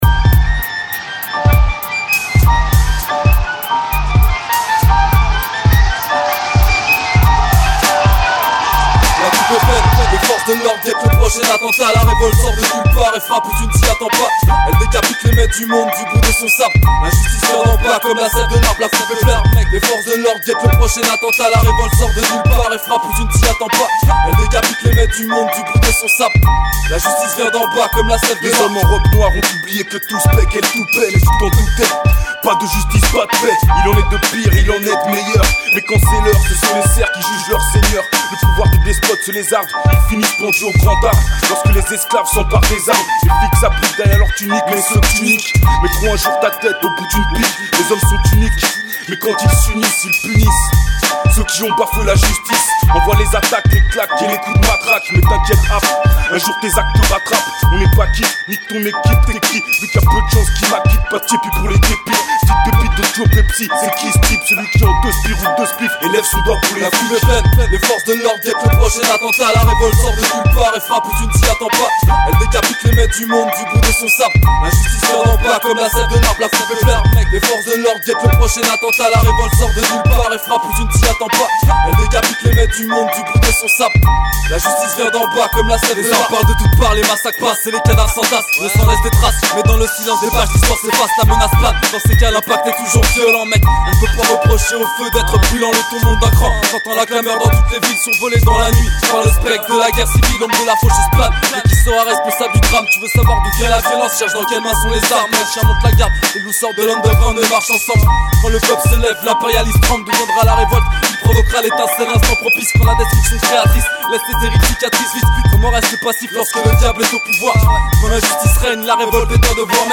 scratch